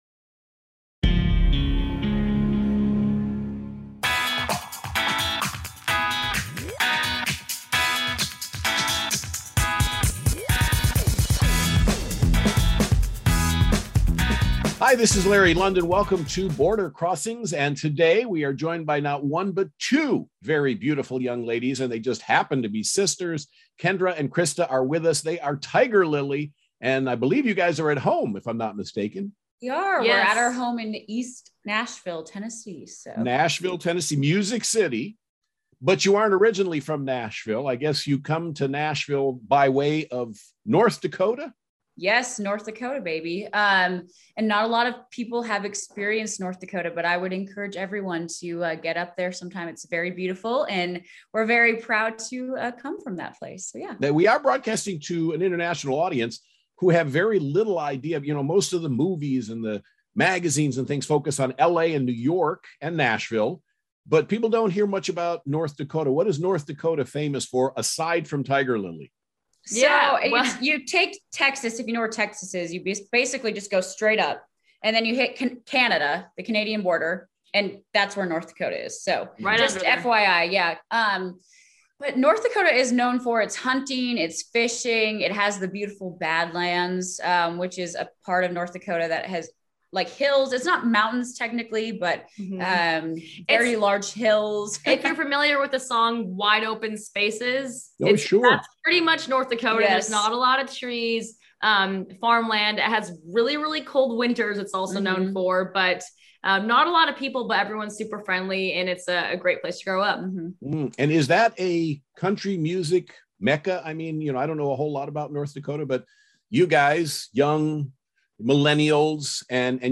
Country music sister-duo